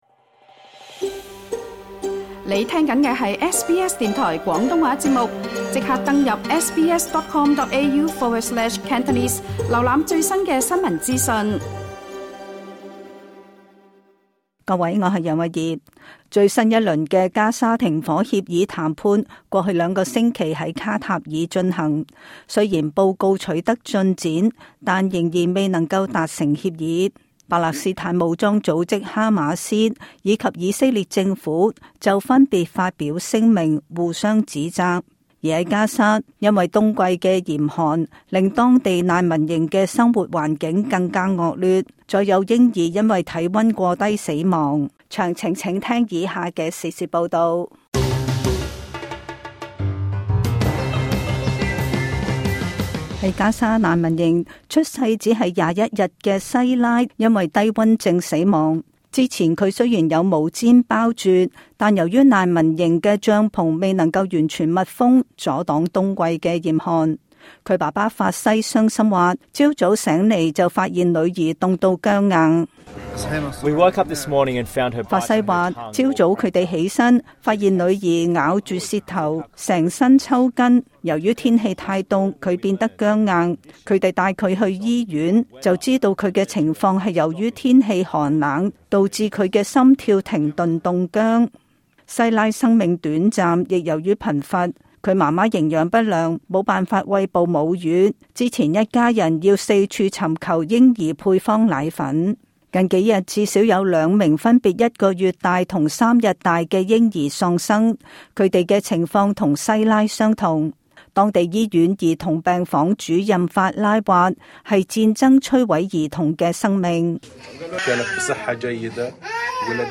請聽今集【時事報道】。